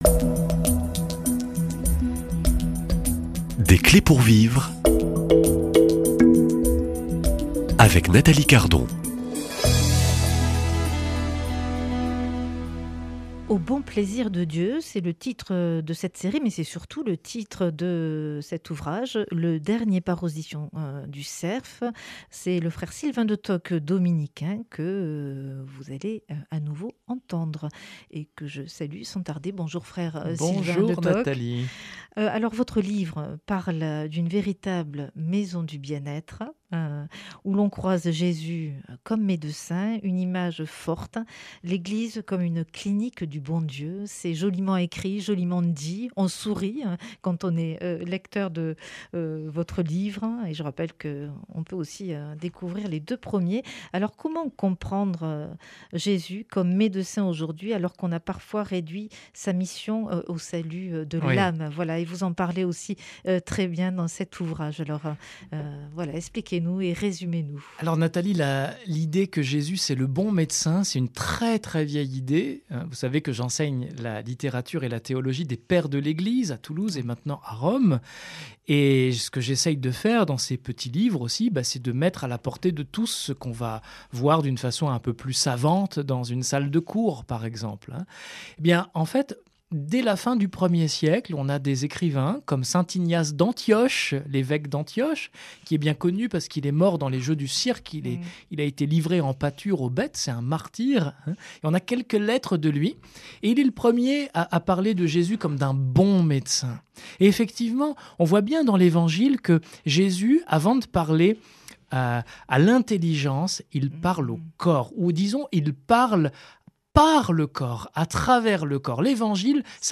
L’ entretien aborde le style et la démarche d’écriture de l’auteur : comment parler de foi avec simplicité, transmettre la joie de croire et toucher un large public.